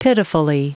Prononciation du mot pitifully en anglais (fichier audio)
Prononciation du mot : pitifully